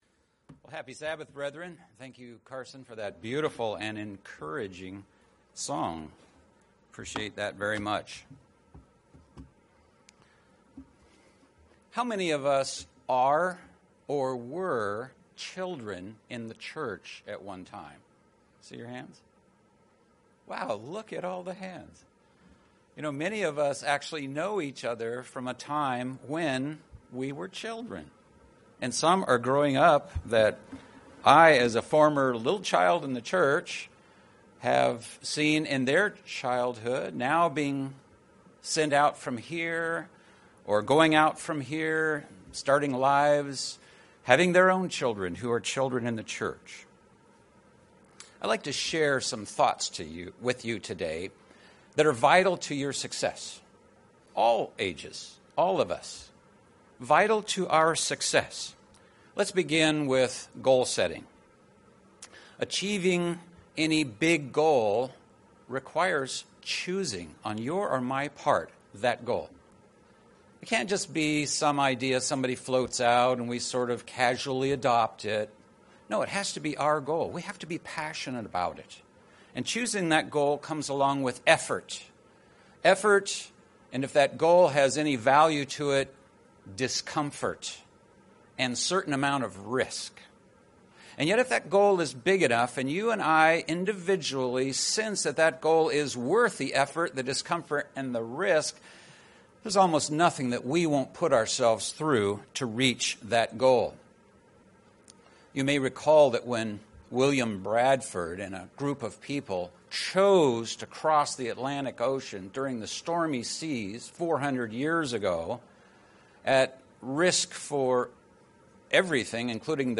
UCG Sermon Satan the Devil Kingdom of God Transcript This transcript was generated by AI and may contain errors.